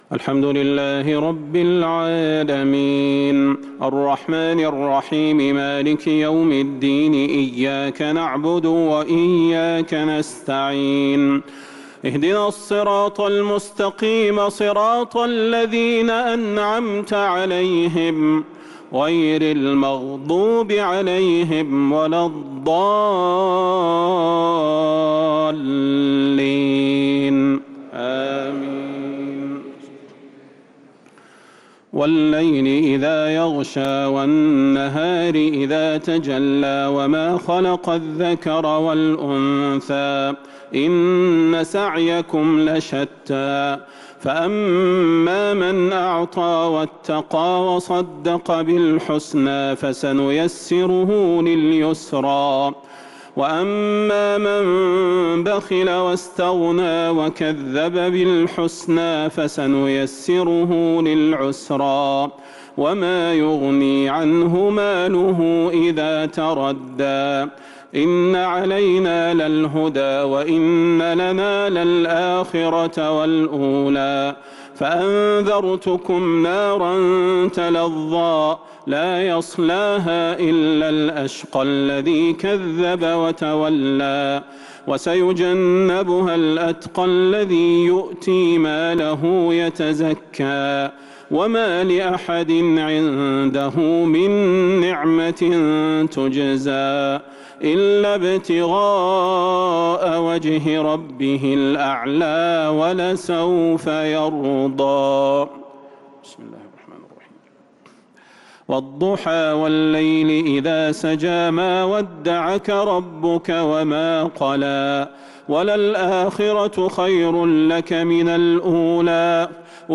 صلاة التراويح ليلة 29 رمضان 1443هـ من سورة الليل حتى سورة الناس | taraweeh prayer The 29th night of Ramadan 1443H from surah Al-Layl to surah An-Nas > تراويح الحرم النبوي عام 1443 🕌 > التراويح - تلاوات الحرمين